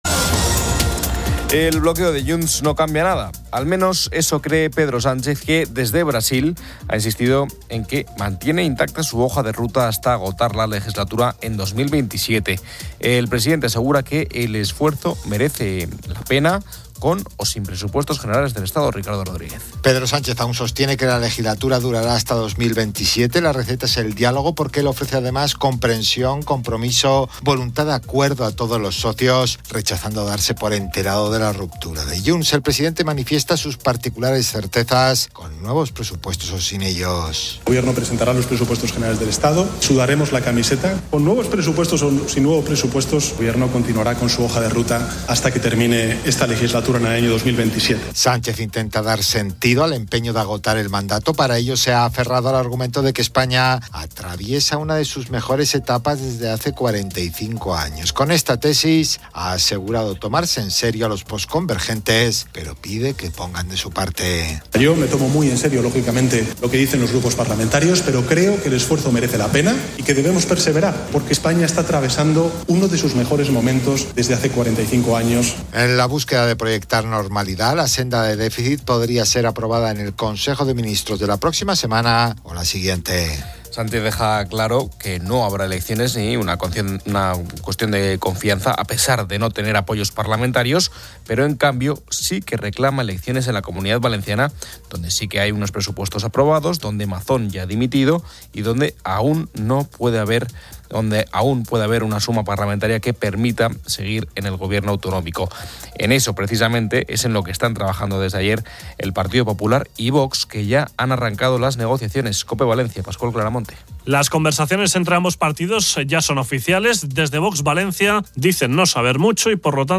Una entrevista personal aborda el dolor de perder un hijo por enfermedad, destacando la búsqueda de esperanza y la creación de una fundación.